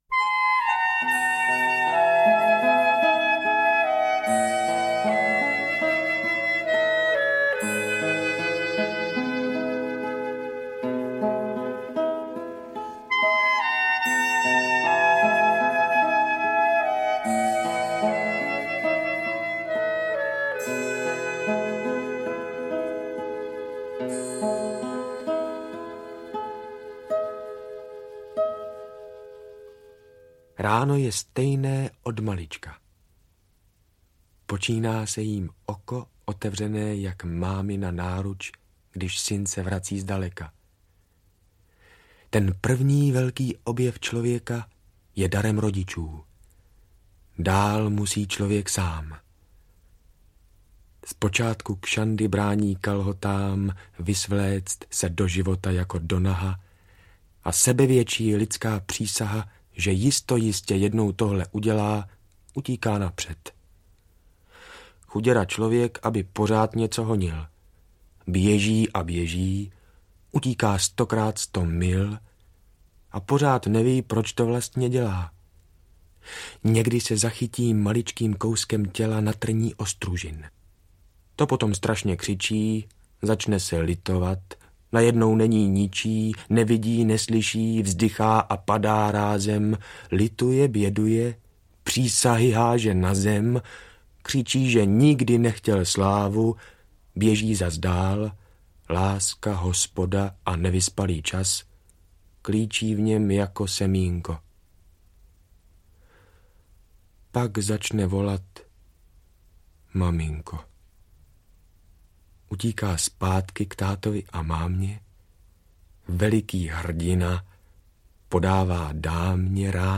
Místenka na Pegasa audiokniha
Ukázka z knihy